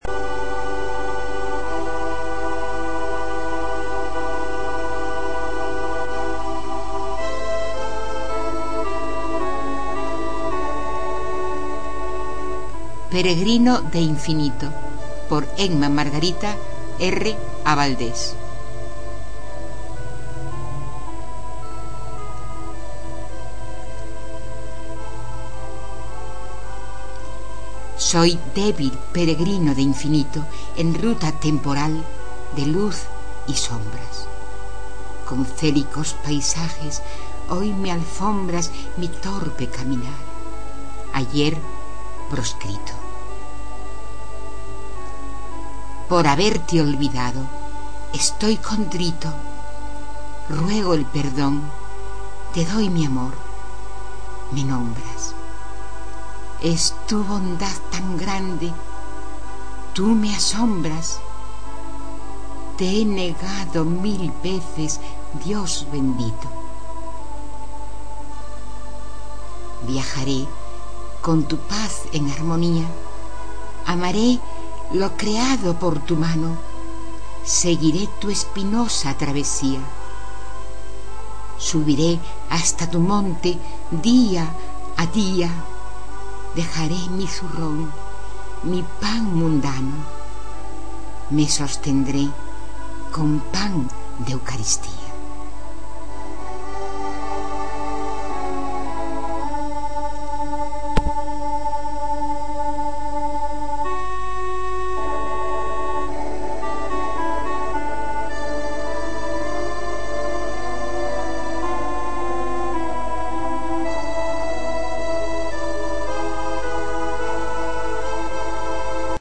Peregrino de infinito, en mp3, recitada por las autora